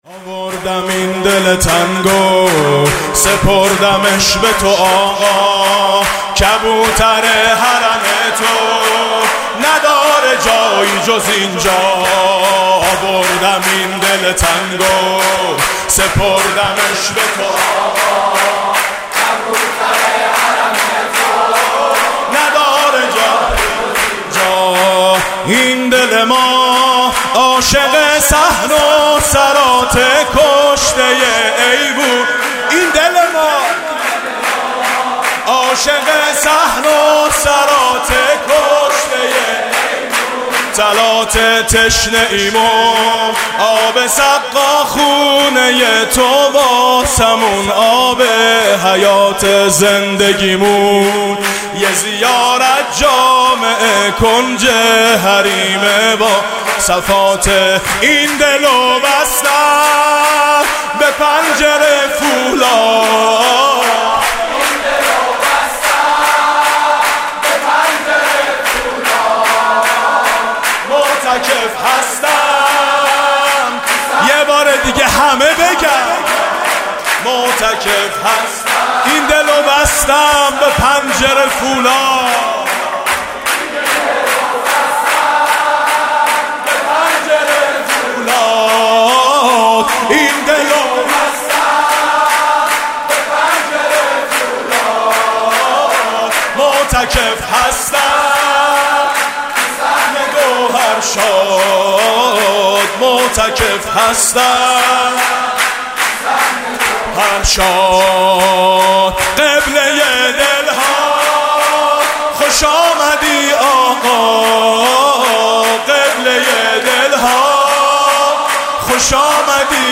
«میلاد امام رضا 1393» سرود: این دل و بستم به پنجره فولاد